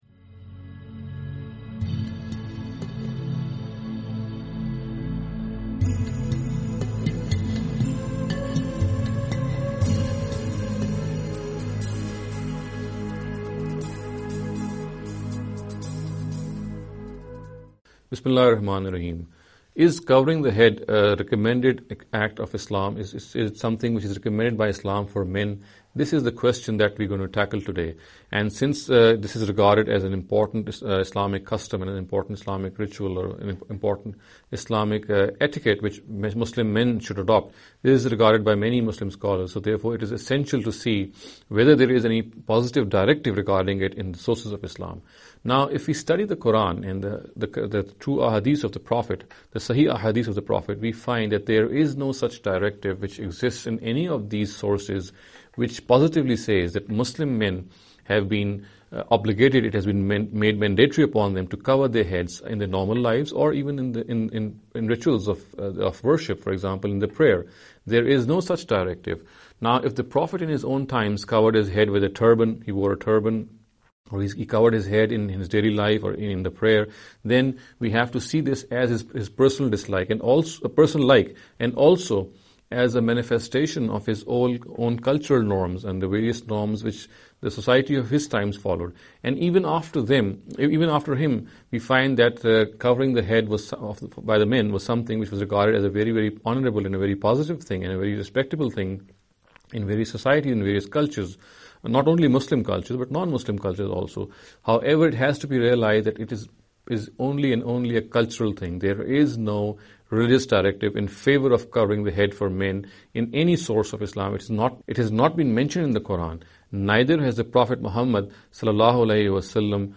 This lecture series will deal with some misconception regarding the Islamic Customs & Etiquette.